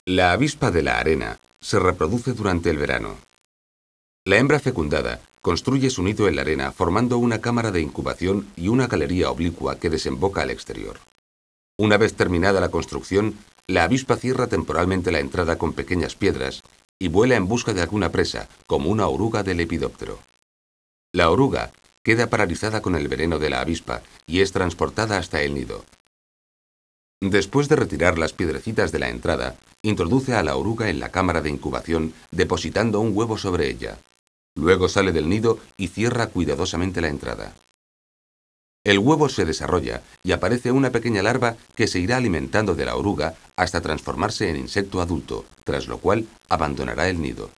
avispa.wav